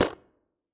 DropItem.ogg